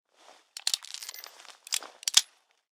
Datei:Wpn pistol32 reload.ogg